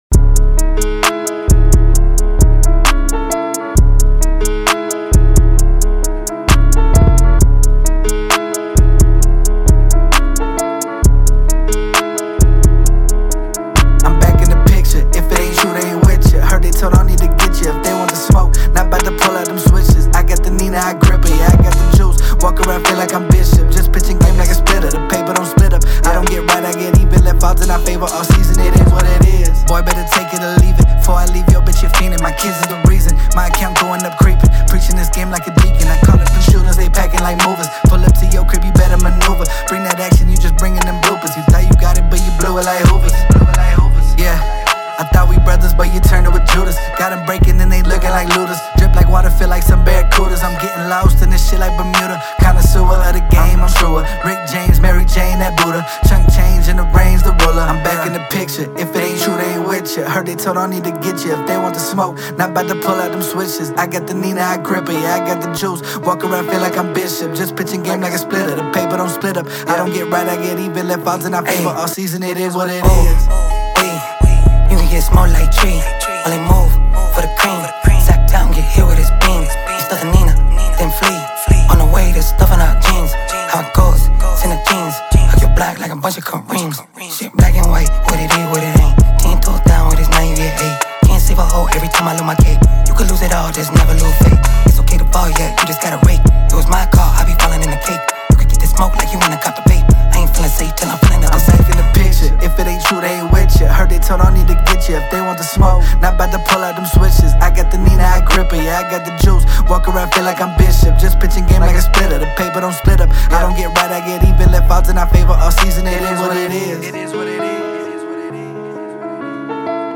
Vibing with my brother on this beat!